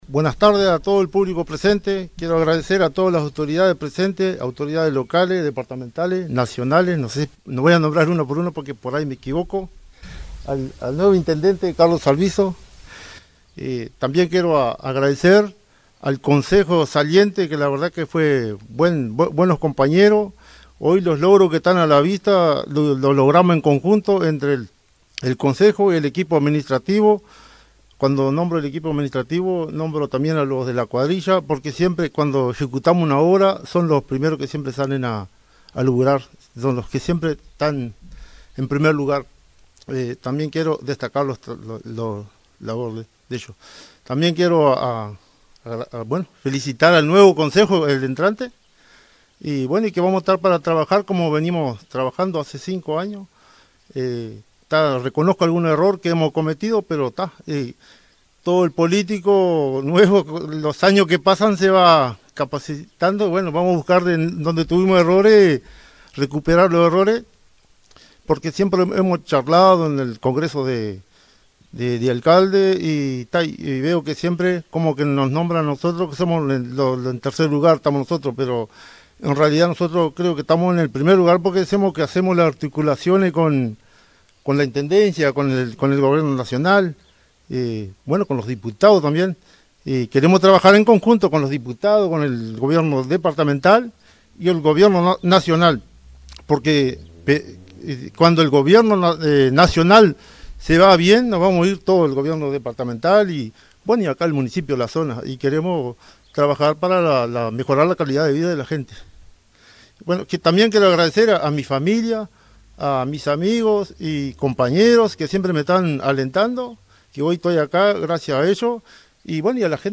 Este sábado se llevó a cabo la ceremonia de asunción de Antonio Tejeira como alcalde del Municipio de Colonia Lavalleja, en un acto que contó con la presencia del intendente de Salto, Dr. Carlos Albisu, junto a autoridades nacionales, departamentales y locales, vecinos y vecinas de la comunidad.